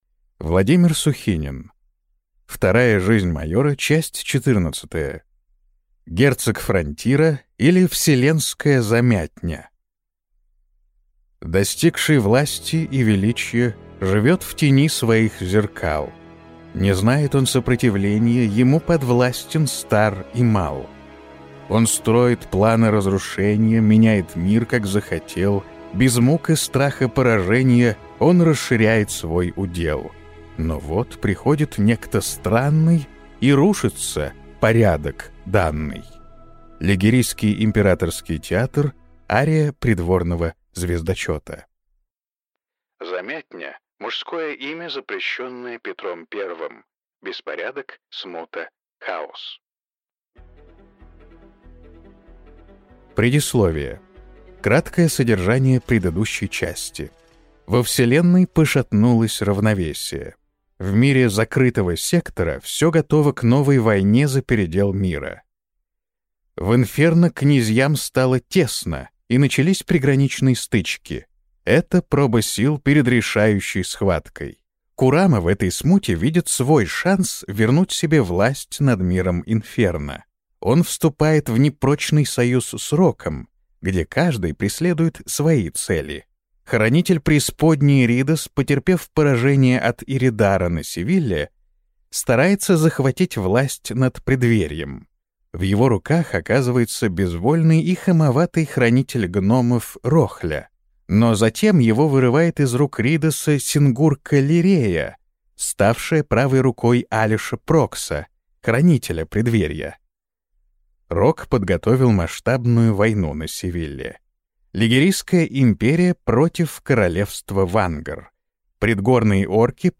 Аудиокнига Герцог фронтира, или Вселенская замятня | Библиотека аудиокниг